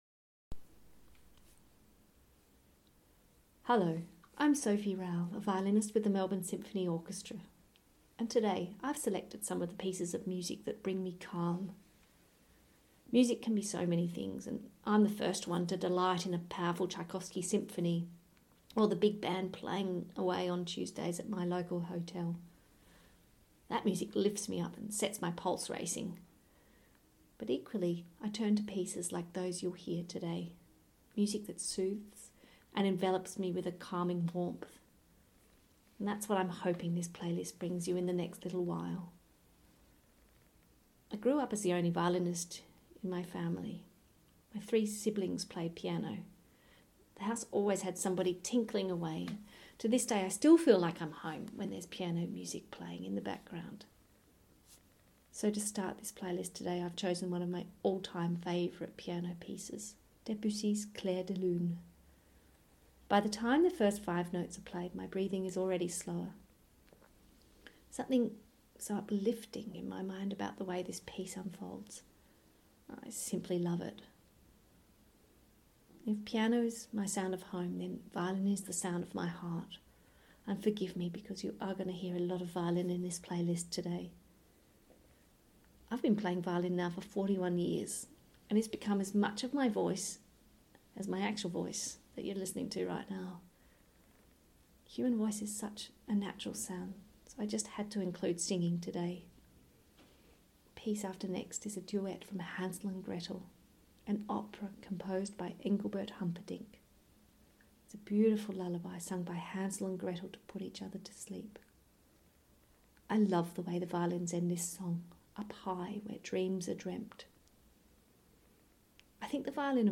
Each musician has provided a short introduction to their playlist exploring the meaning the music has for them personally.